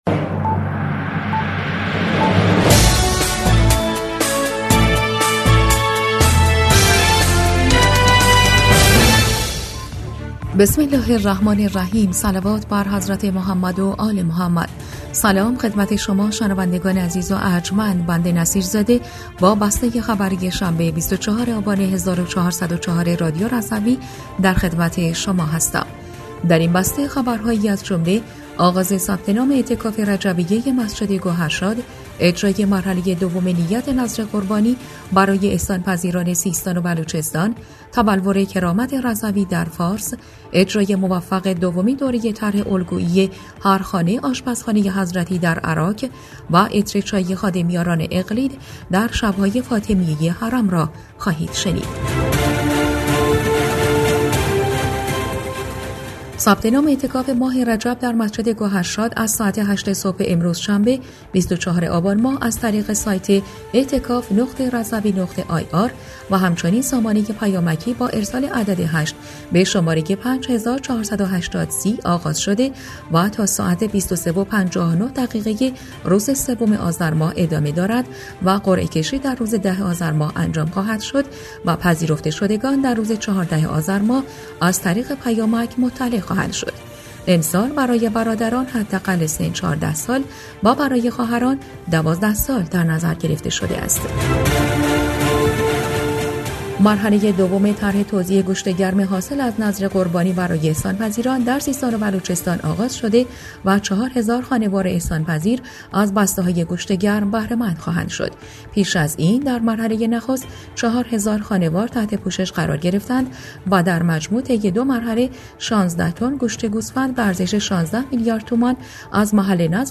بسته خبری ۲۴ آبان ۱۴۰۴ رادیو رضوی؛